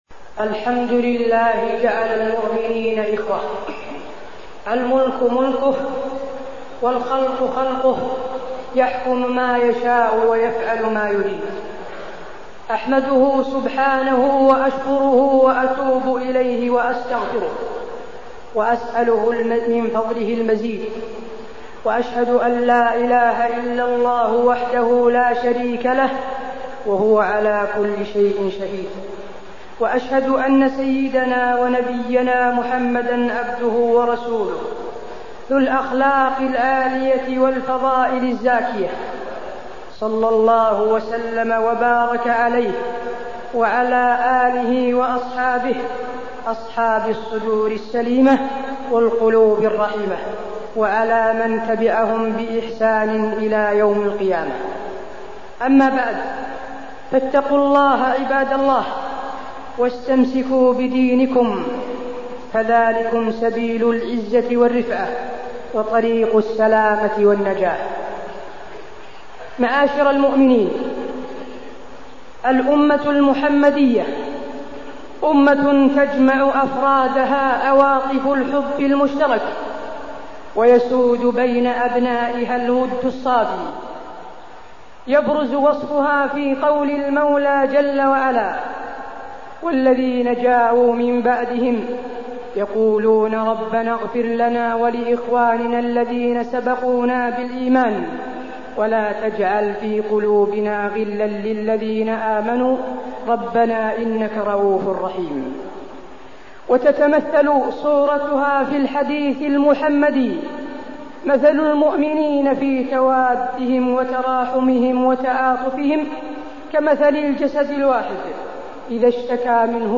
تاريخ النشر ٢٣ جمادى الأولى ١٤٢٠ هـ المكان: المسجد النبوي الشيخ: فضيلة الشيخ د. حسين بن عبدالعزيز آل الشيخ فضيلة الشيخ د. حسين بن عبدالعزيز آل الشيخ الحسد